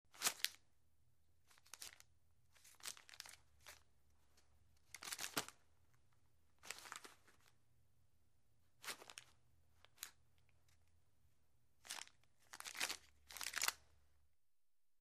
Crujido de papel crujiendo: